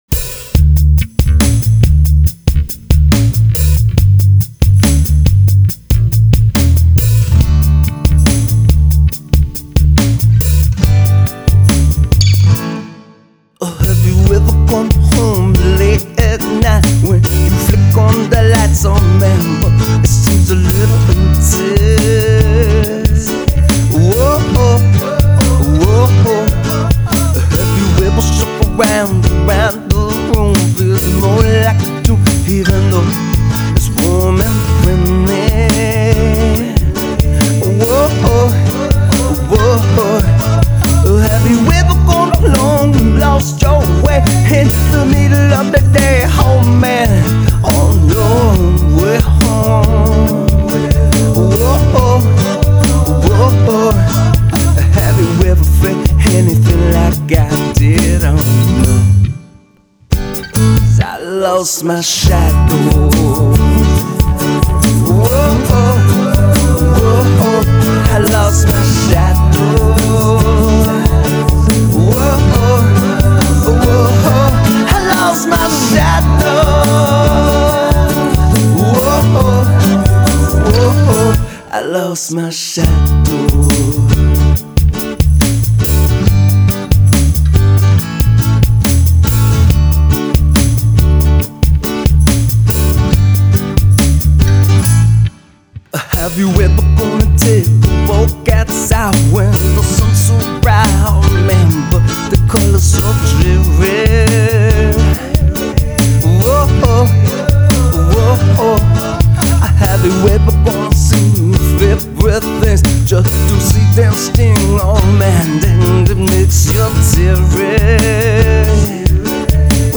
Lost My Shadow (reggae/rock mix)
drums are drum machine.
(shaker and tambourine are real)
empty middle area will have a guitar solo in it at some point as well.